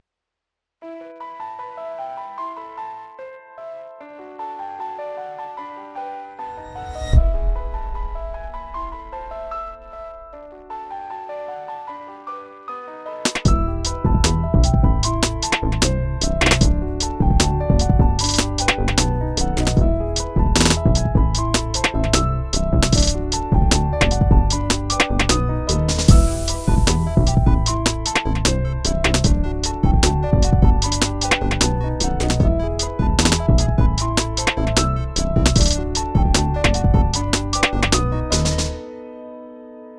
This track has helped lots of people relax, even though it's mellow.
Inspired by rain, this is a true minimalist track with one motif going 'round and 'round.